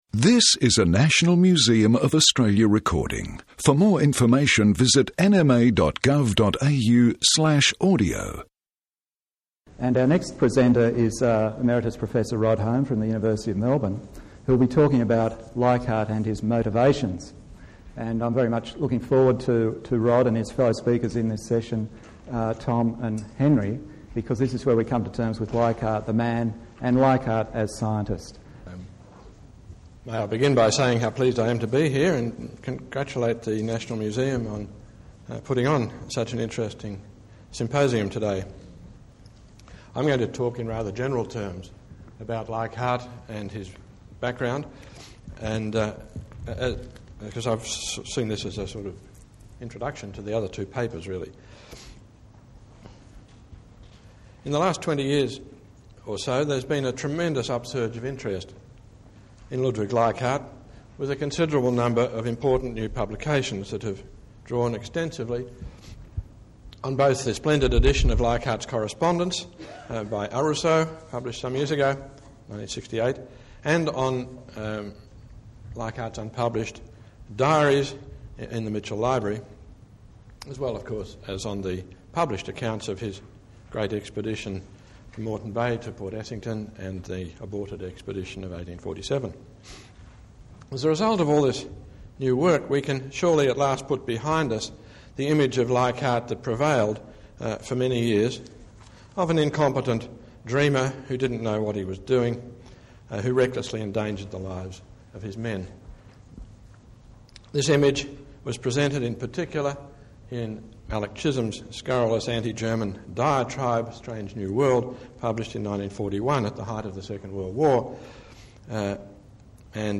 NOTE: audio loops from 18:40 on.